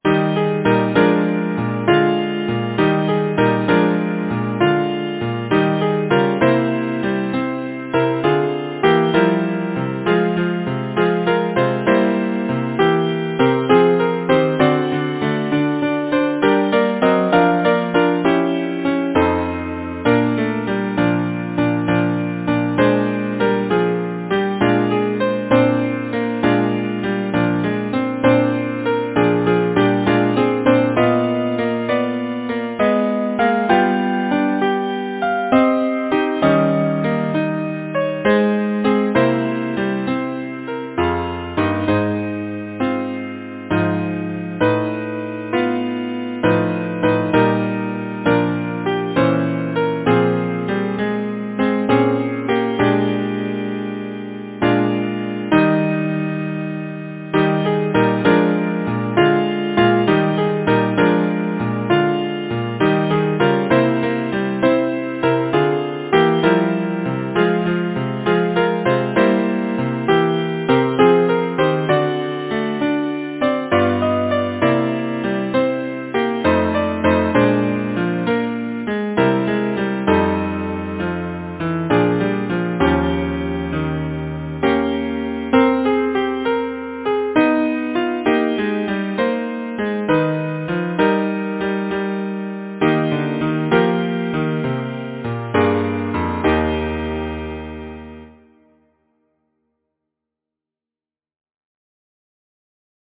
Title: Airs of summer, softly blow Composer: Henry Elliot Button Lyricist: Number of voices: 4vv Voicing: SATB Genre: Secular, Partsong
Language: English Instruments: A cappella